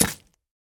Minecraft Version Minecraft Version 1.21.5 Latest Release | Latest Snapshot 1.21.5 / assets / minecraft / sounds / block / pumpkin / carve2.ogg Compare With Compare With Latest Release | Latest Snapshot
carve2.ogg